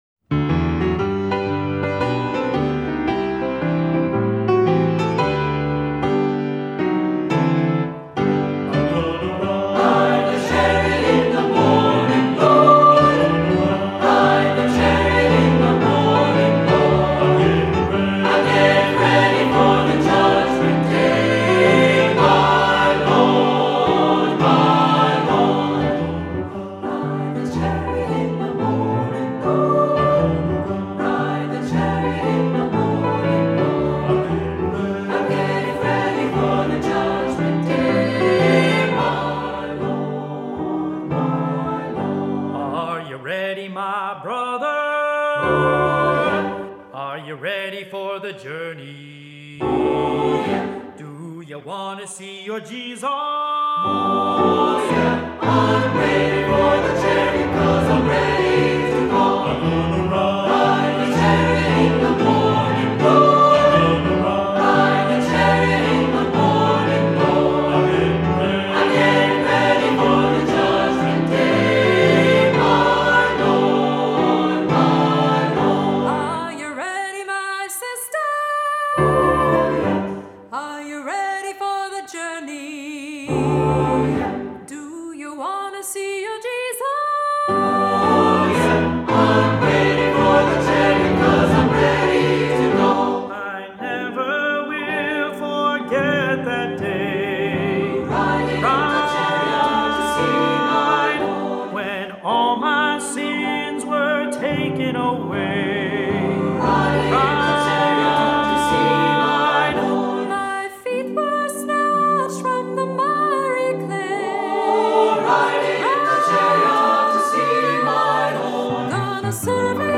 Voicing: SATB; Soloist